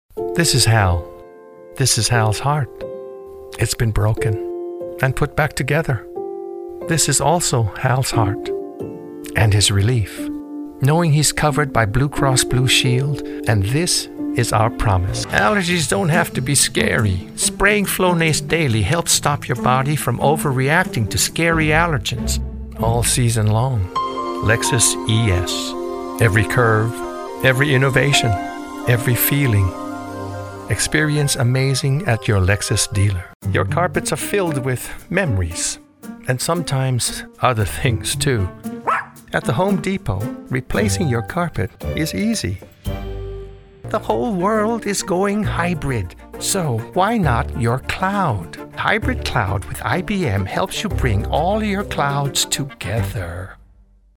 Commercial Demo